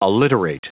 Prononciation du mot : alliterate
alliterate.wav